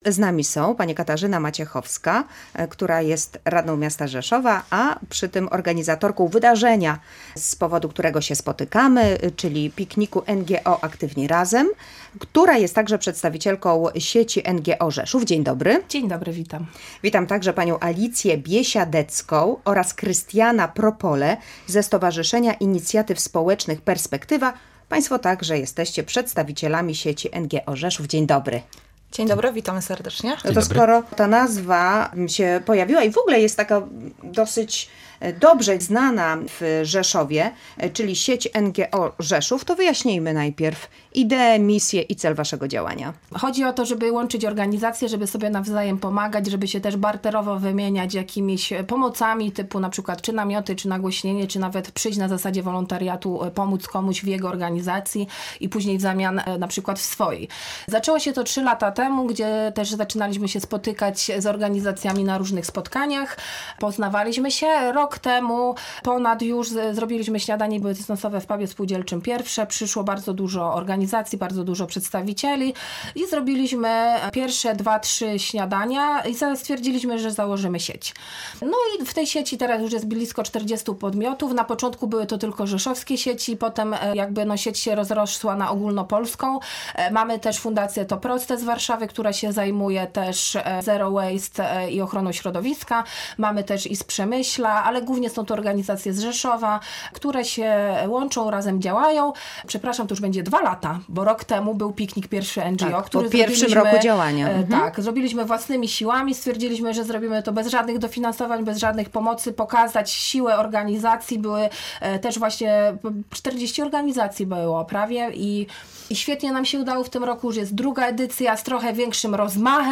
O szczegółach tego wydarzenia rozmawiała z gośćmi